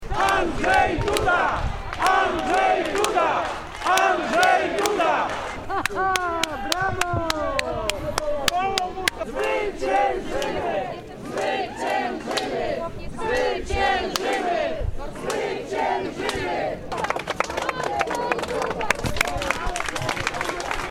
Mieszkańcy zgotowali prezydentowi prawdziwą owację: